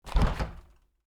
Fridge Open Sound Effect 2 The sound of a refrigerator or freezer door opening
fridge-2-open.wav